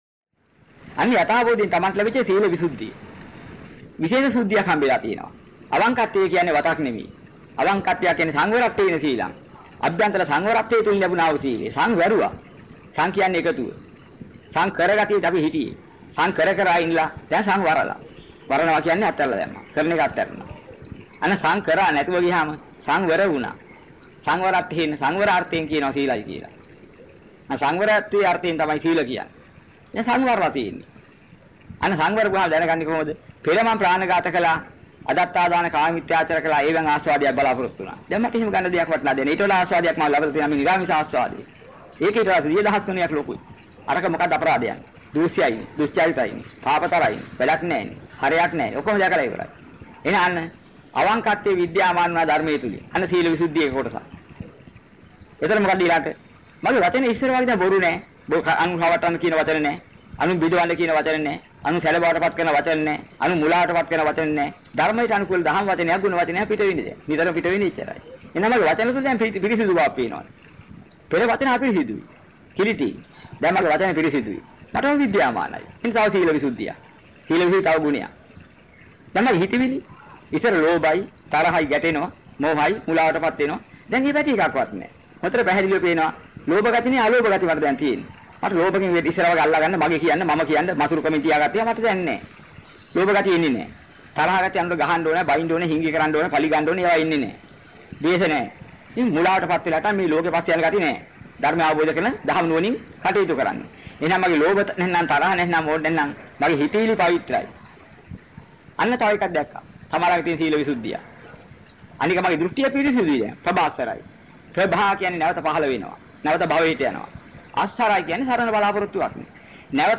ආර්ය කර්මස්ථාන කිහිපයක් පැහැදිලි කර දෙන්න - පෙර දේශනාවේ ඉතිරි කොටස